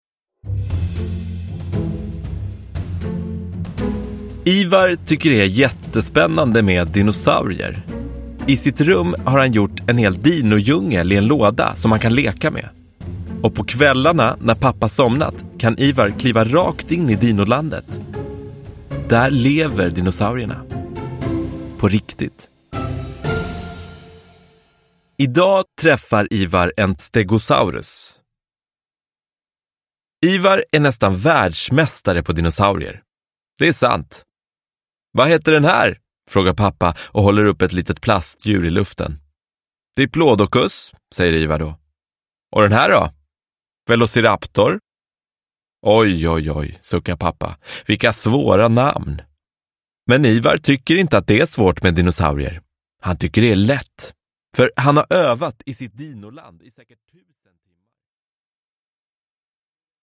Ivar träffar en stegosaurus – Ljudbok – Laddas ner